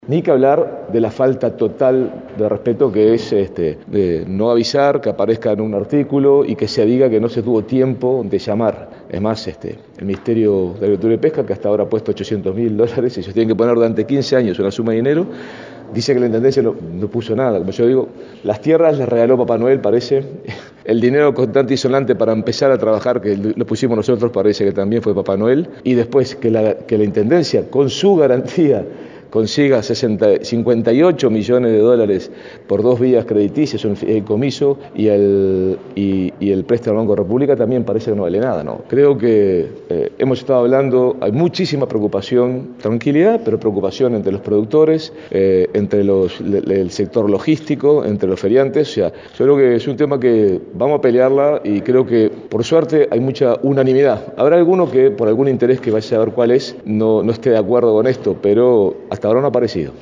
«Las tierras [donde está la Unidad Agroalimentaria] parece que las puso Papá Noel, el dinero parece que lo puso papá Noel», dijo en rueda de prensa tras la reunión.
Escuchá lo que dijo Daniel Martínez: